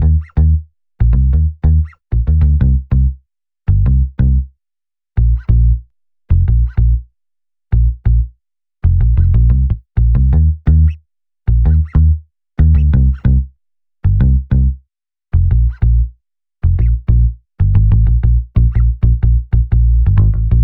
09 bass A1.wav